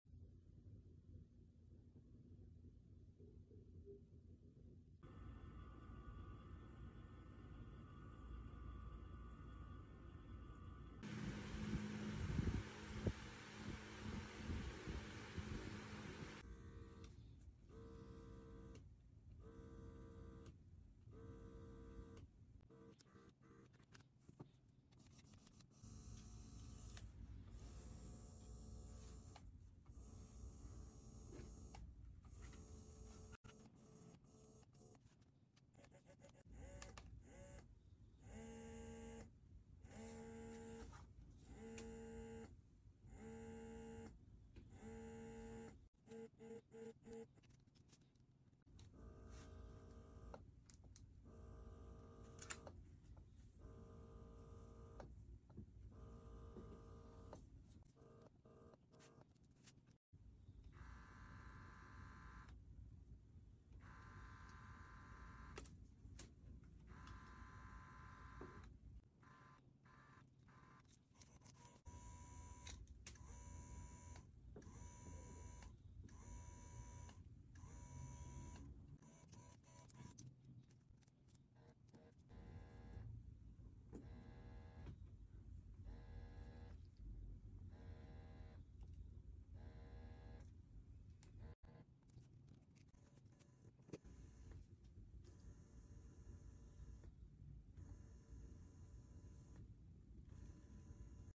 All pumps are within the quiet to moderate dB range. The difference mainly lies in the preference for timbre and frequency.